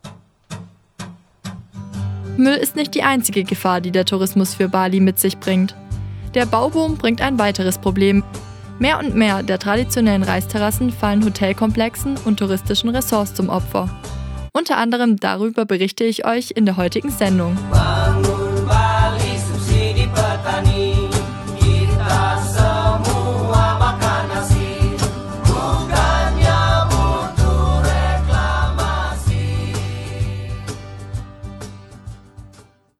487_Teaser.mp3